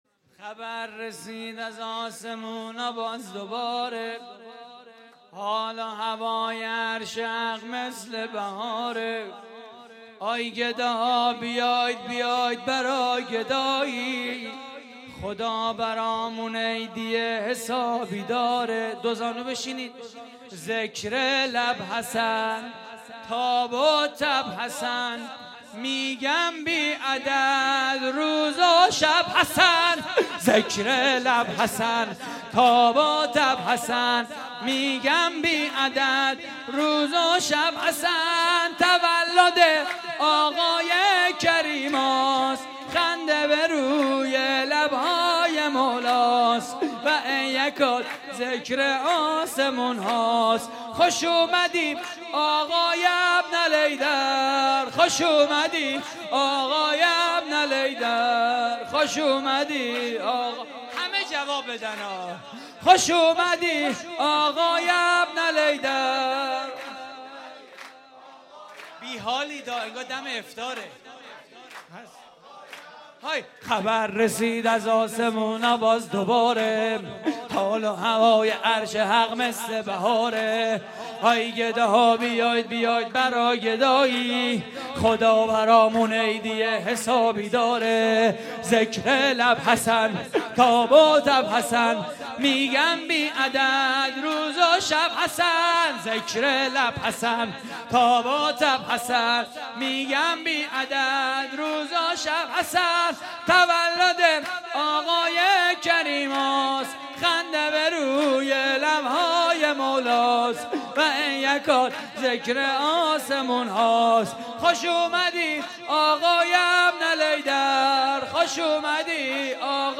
مراسم جشن ولادت امام حسن مجتبی (ع) / هیئت الزهرا (س) – نازی‌آباد؛ 17 فروردین 1402
صوت مراسم:
سرود: تولد آقای کریماس؛ پخش آنلاین |